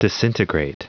Prononciation du mot disintegrate en anglais (fichier audio)
Prononciation du mot : disintegrate